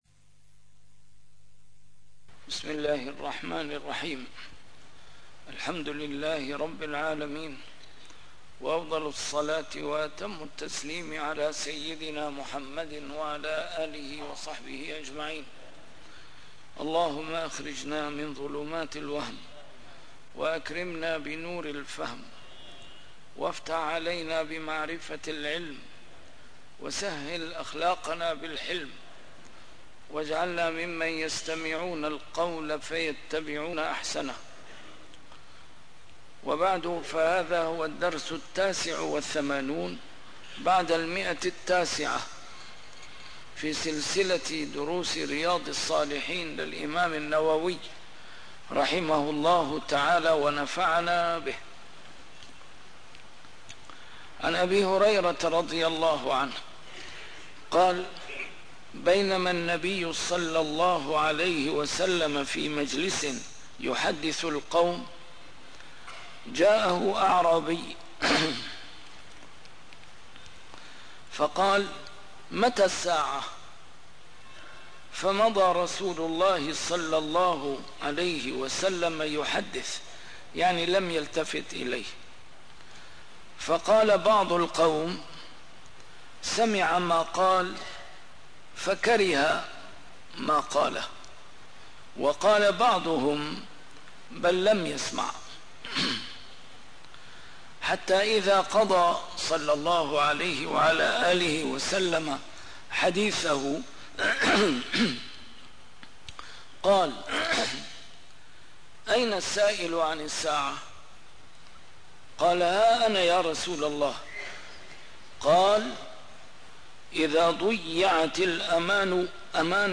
A MARTYR SCHOLAR: IMAM MUHAMMAD SAEED RAMADAN AL-BOUTI - الدروس العلمية - شرح كتاب رياض الصالحين - 989- شرح رياض الصالحين: بابُ المنثورات والمُلَح